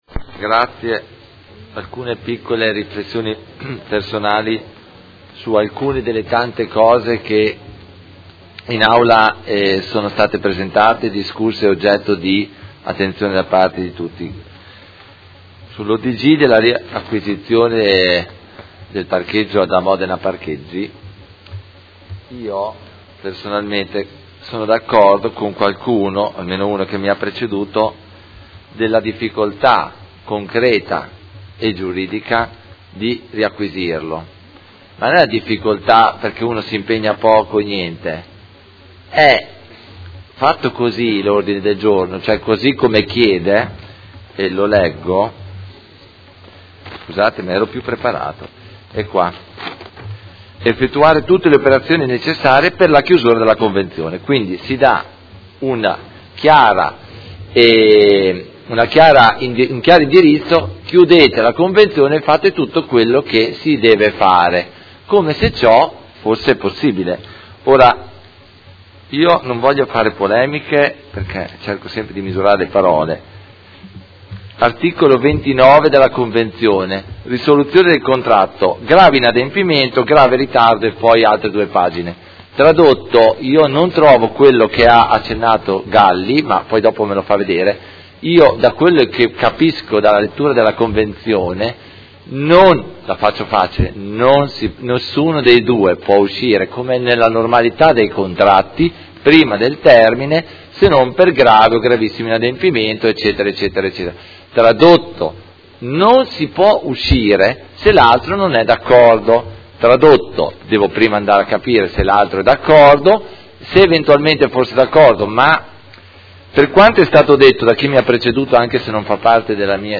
Antonio Carpentieri — Sito Audio Consiglio Comunale